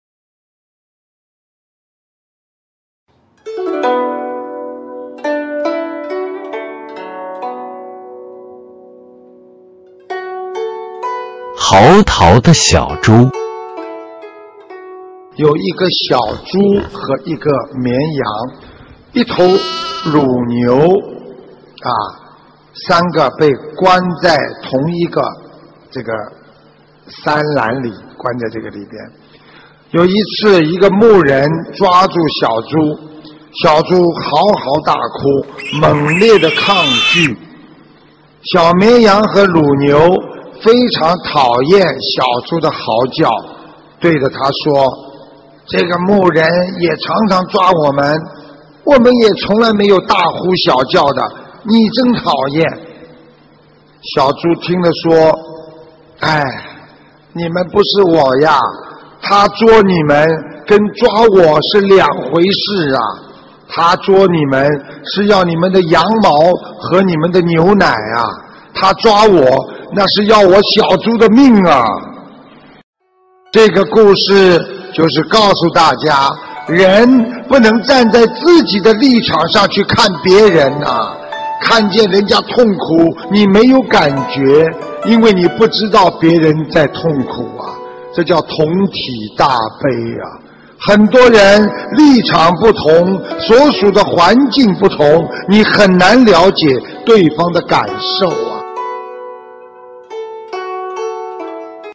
音频：《嚎啕的小猪》师父讲故事！摘自_2015年06月22日.香港智慧妙语！